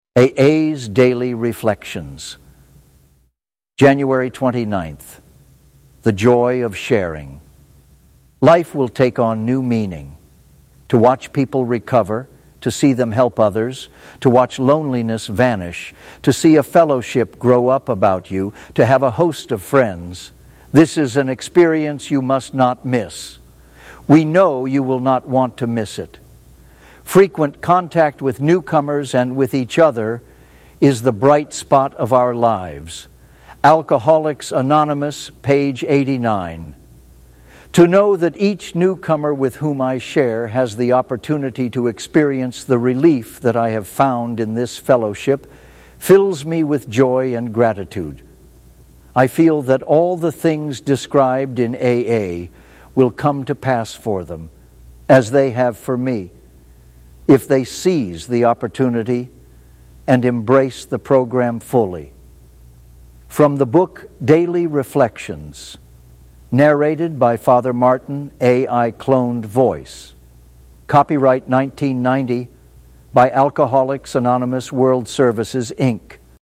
Daily Reflections